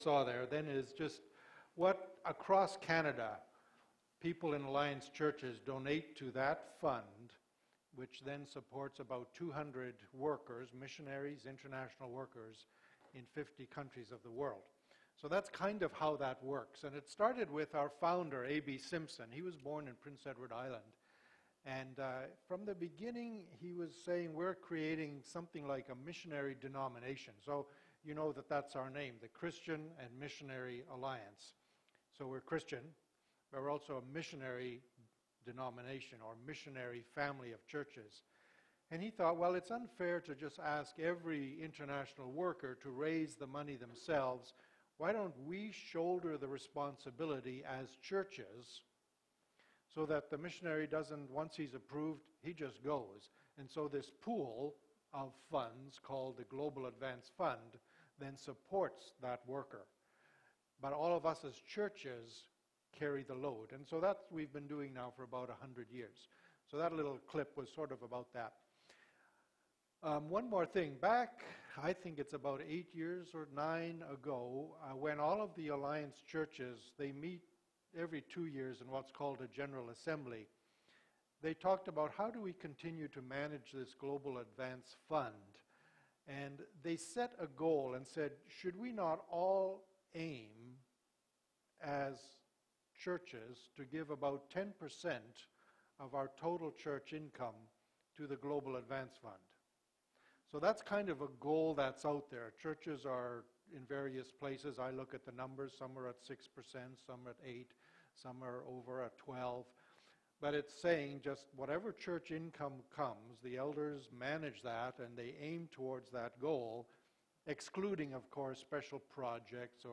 Sermons | Peace River Alliance Church